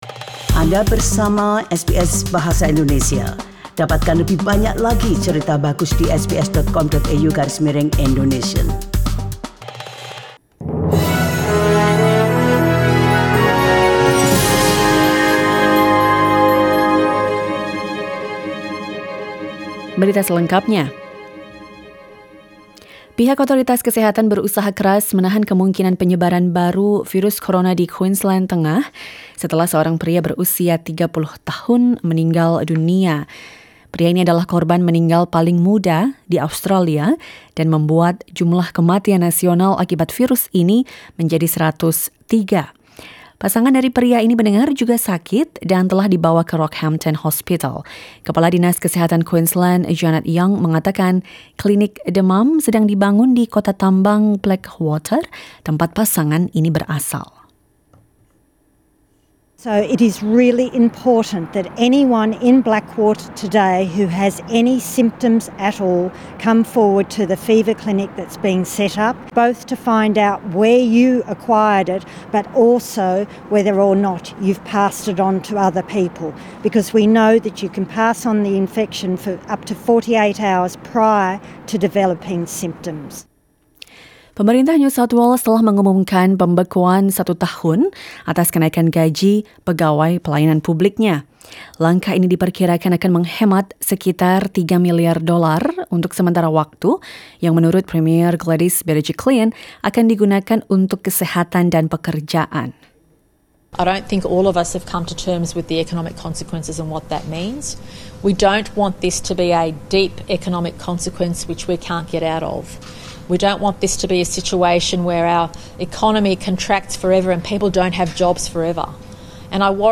SBS Radio news in Indonesian, 27 May 2020.